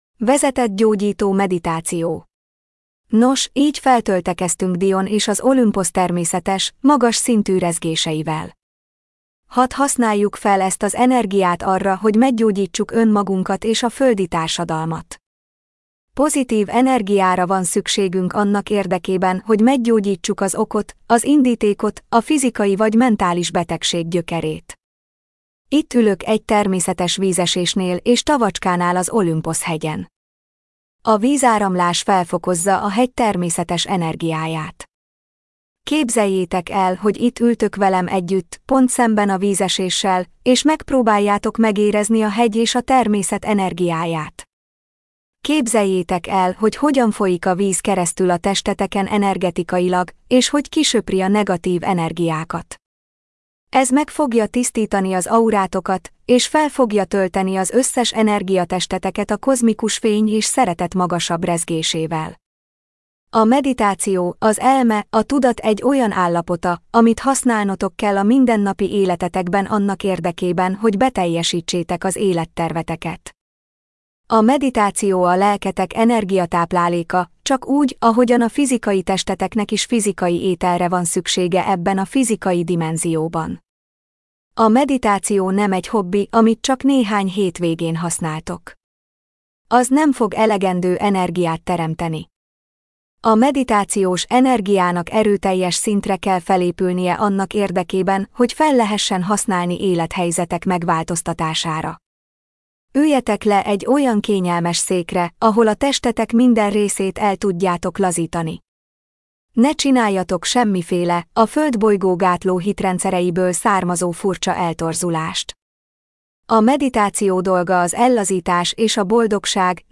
Magyar nyelvű gépi felolvasás
Vezetett gyógyító meditáció (23. rész)